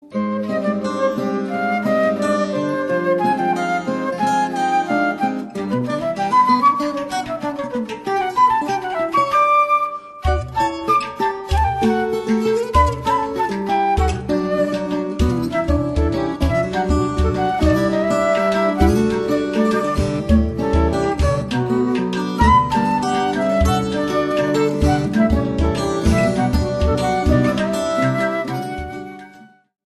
tango caboclo